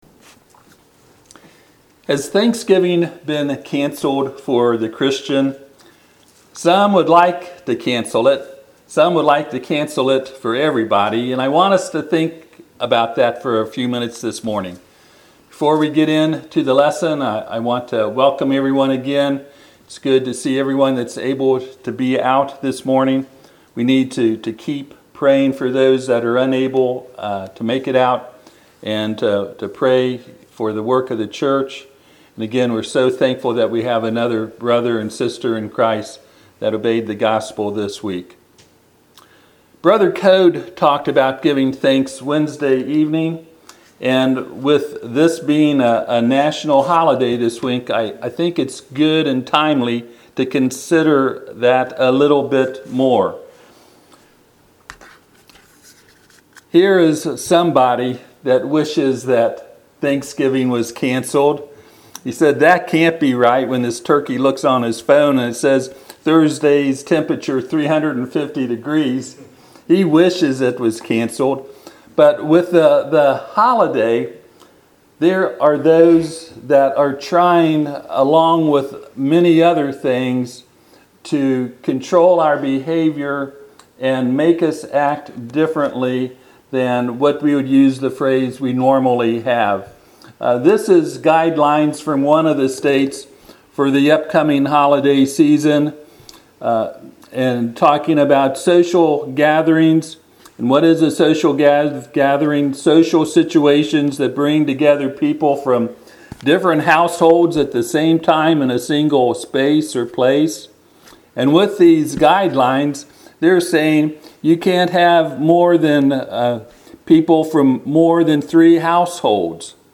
Passage: Psalm 107:1-9 Service Type: Sunday AM « The Providence Of God In The Life Of Joseph Noah.